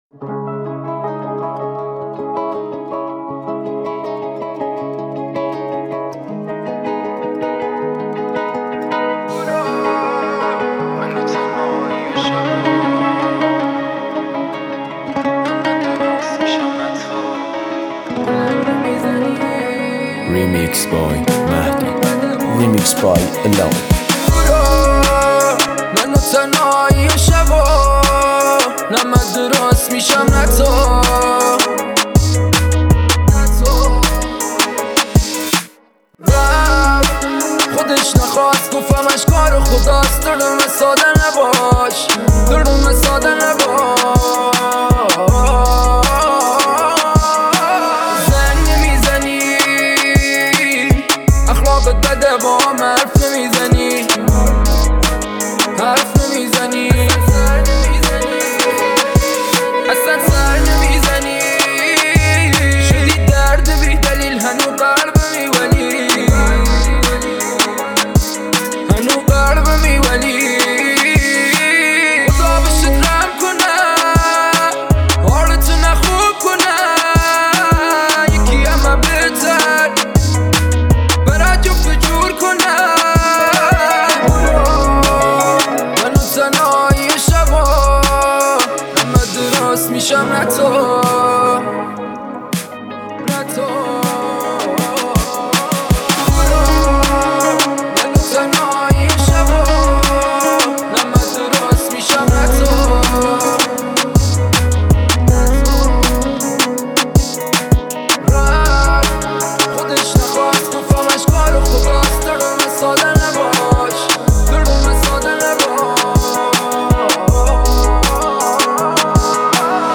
دانلود آهنگ سبک هیپ هاپ
ریمیکس جدید